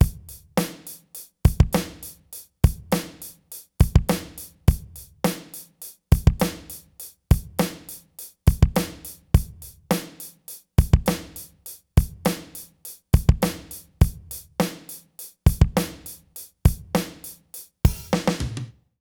British REGGAE Loop 105BPM.wav